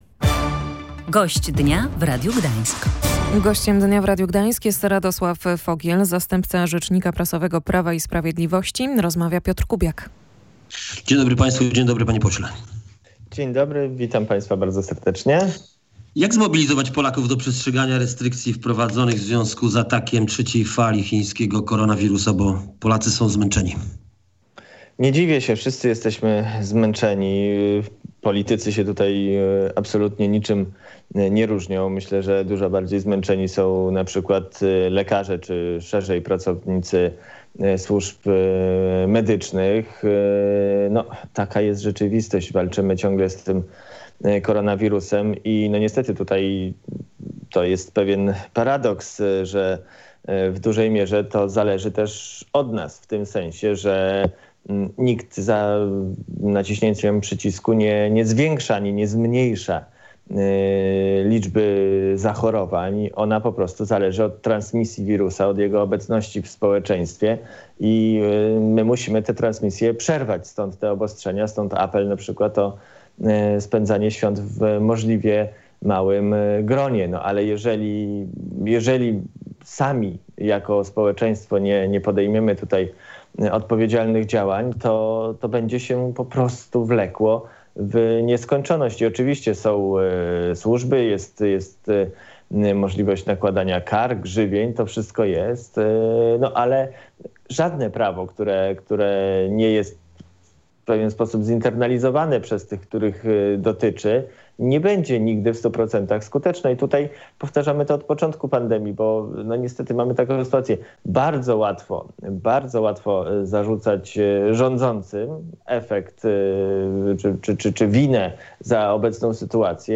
Rząd ma przygotowane kolejne zasady bezpieczeństwa, jeśli wprowadzone od soboty obostrzenia nie spowodują spadku liczby zakażeń – mówił w Radiu Gdańsk Radosław Fogiel, zastępca rzecznika prasowego Prawa i Sprawiedliwości. Niewykluczony jest też zakaz przemieszczania się, ale jest to ostateczność – dodaje.